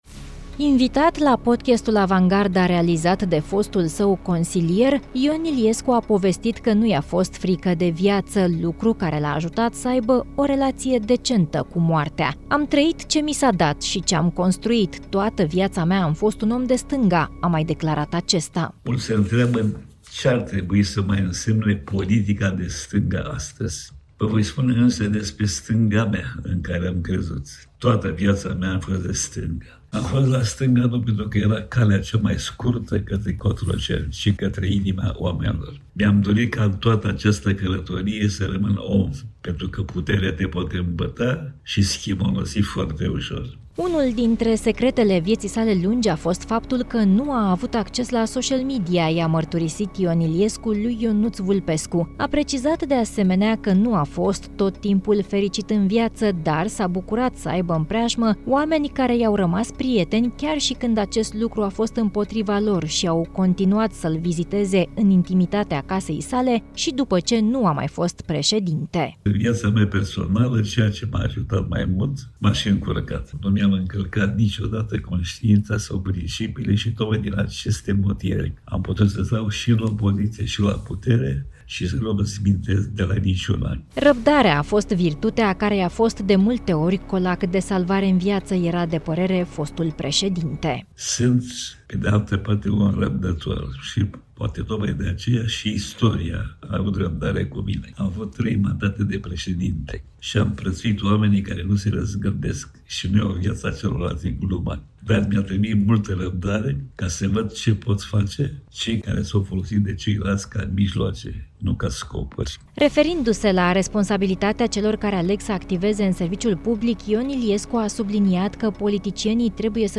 „Am trăit ce mi s-a dat și ce am construit”, așa își descria fostul președinte, Ion Iliescu, viața în urmă cu câteva luni, într-un interviu pe care i l-a acordat fostului său consilier, Ionuț Vulpescu.